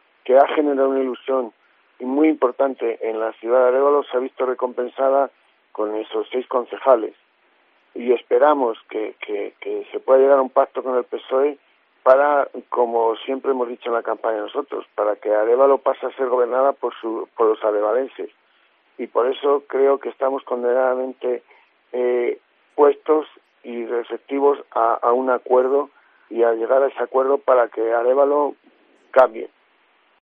León ha lanzado desde los micrófonos de COPE Ávila el guante al PSOE y espera su apoyo, para que “por fin Arévalo sea gobernada por los arevalenses”.